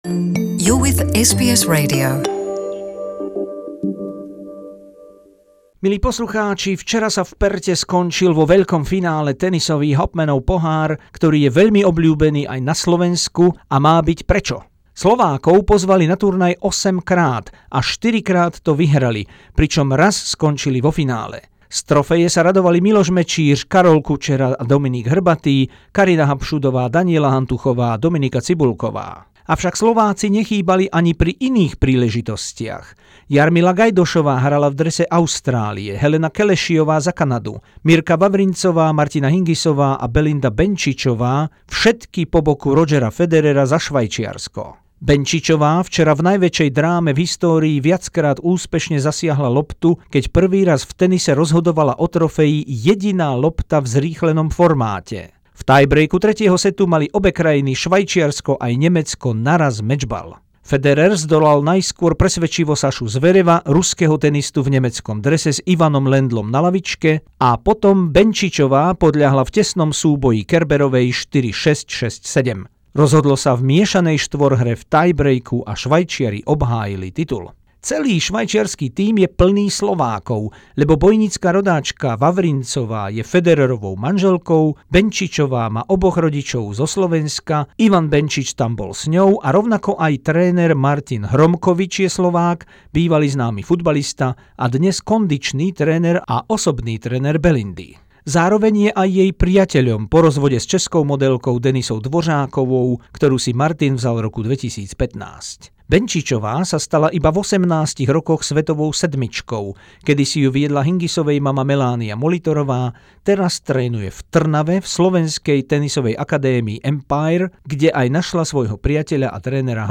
Rozhovor s tenistkou Belindou Benčičovou po prvenstve v Hopmanovom pohári